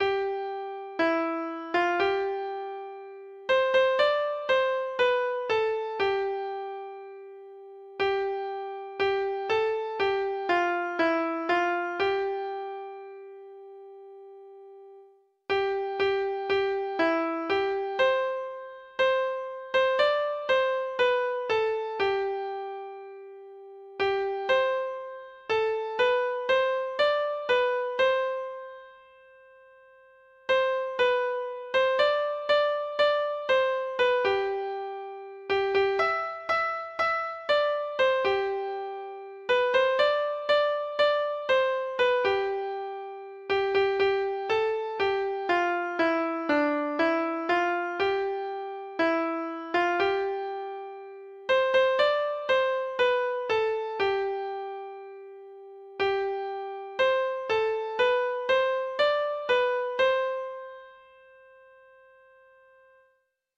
Treble Clef Instrument version
Folk Songs